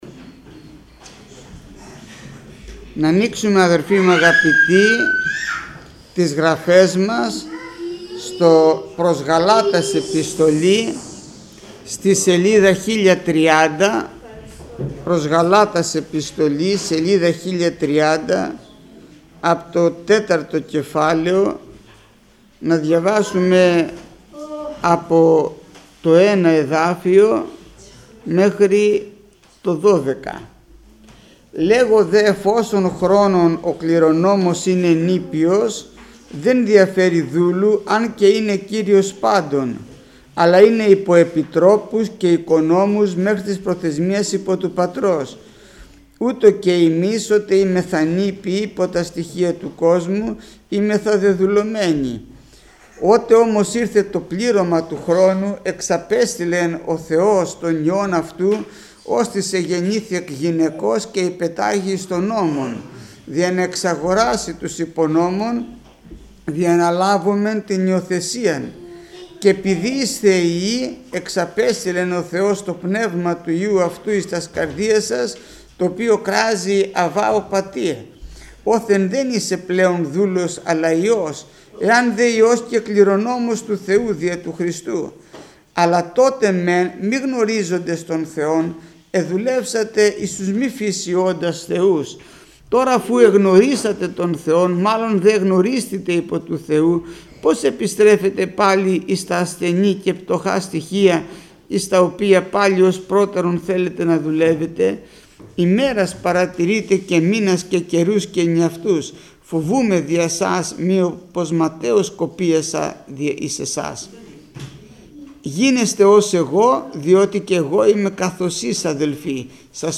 Μηνύματα Θείας Κοινωνίας